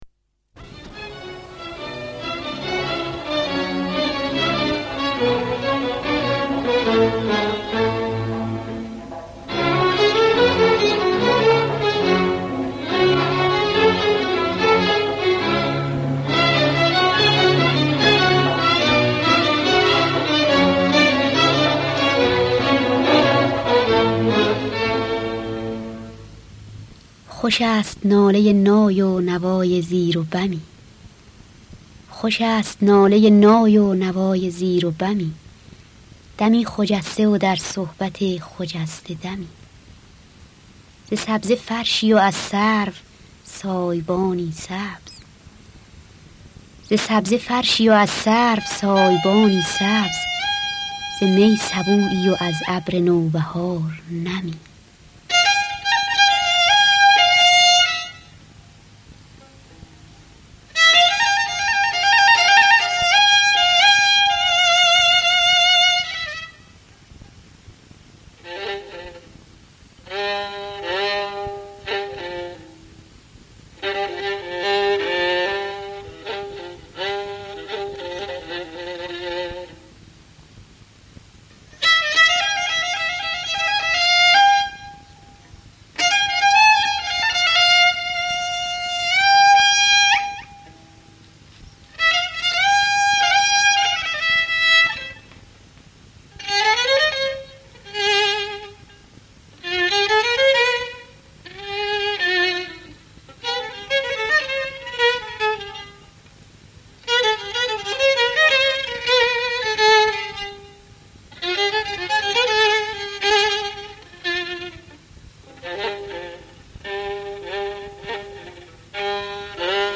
در دستگاه سه‌گاه